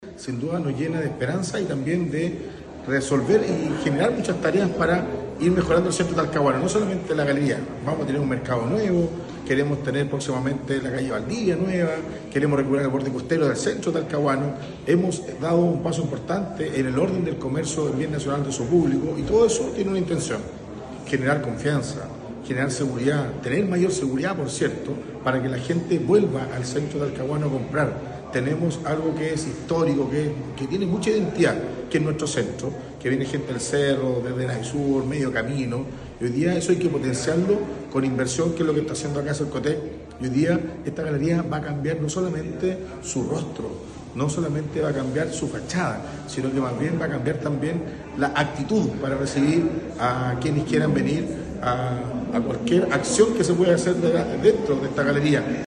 El alcalde Eduardo Saavedra valoró el impacto de esta iniciativa y comentó que se ha dado “un paso importante para generar confianza y seguridad para que la gente vuelva al centro.